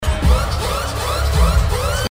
Возможно вы знаете где скачать семплы с кучей именно таких бодрящих и стильных, но этот очень нужен)